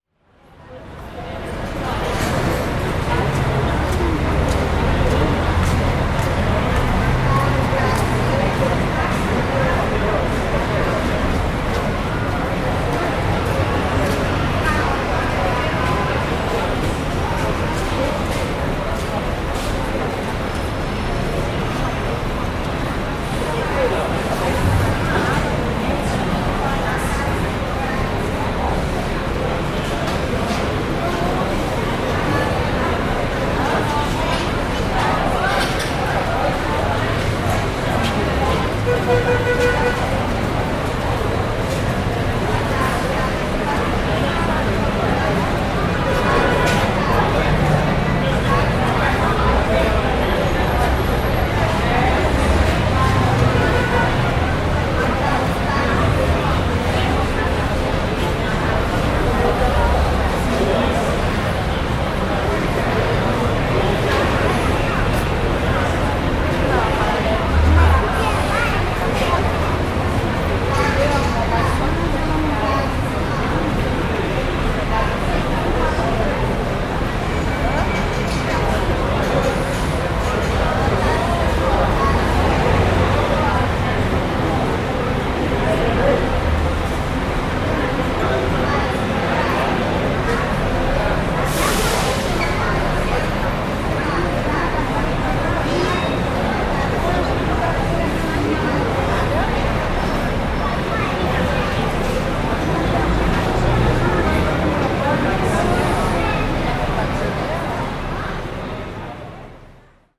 Звуки автовокзала
Шум и голоса в атмосфере автовокзала